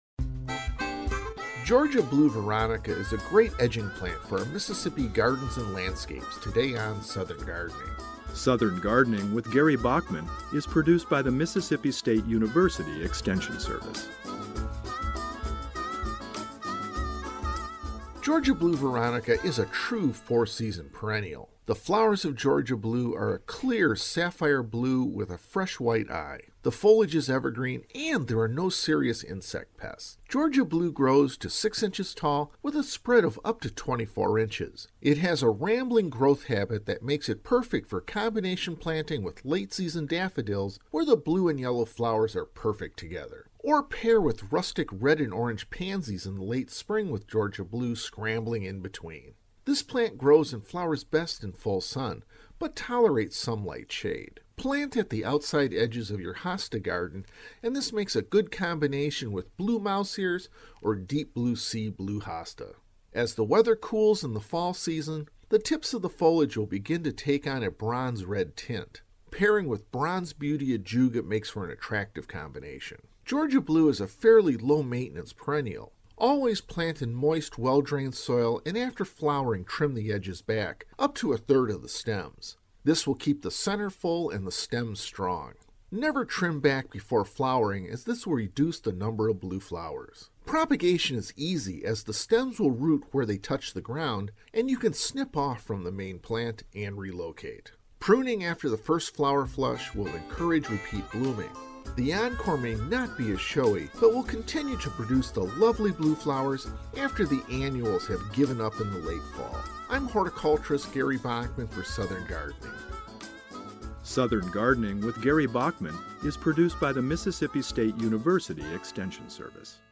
Host: